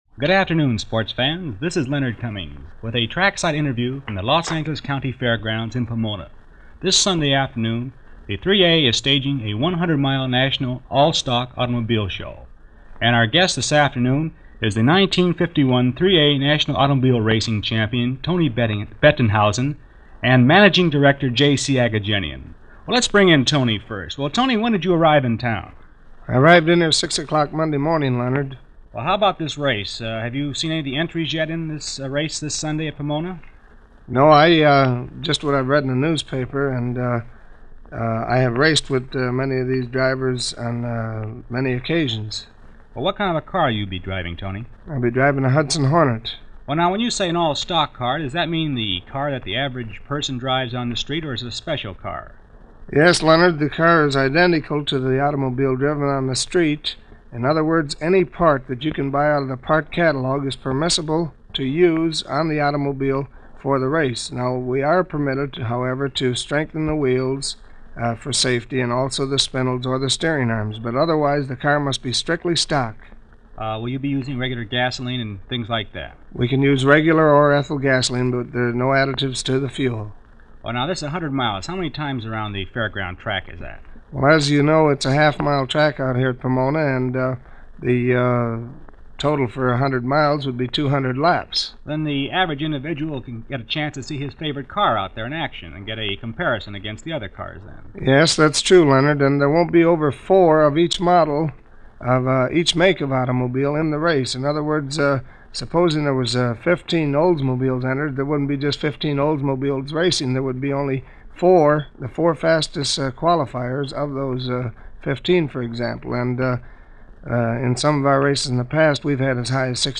Pomona Fairgrounds
To get an idea of the atmosphere around car racing in Southern California at the time, here is an interview featuring race-car driver Tony Bettenhauser and famed Race promoter J.C. Agajanian – one of the icons of Car Culture in the country, and certainly in Southern California, as broadcast on February 24, 1952.